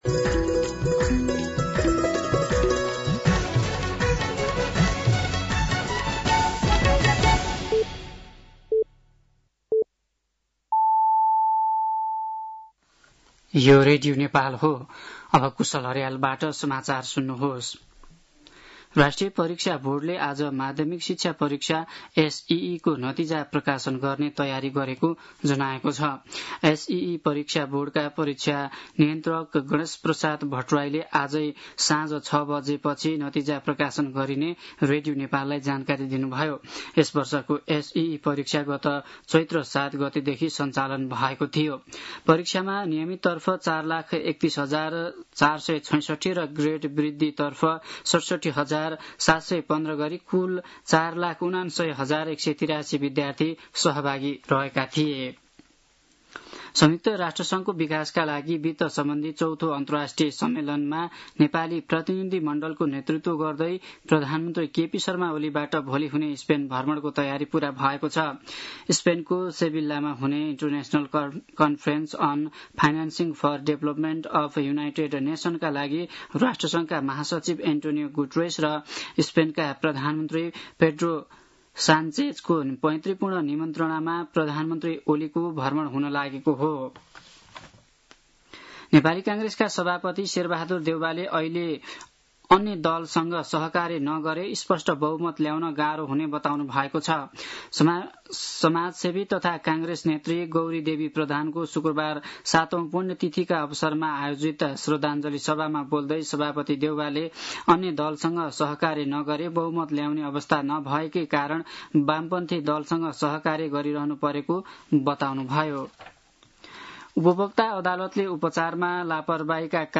साँझ ५ बजेको नेपाली समाचार : १३ असार , २०८२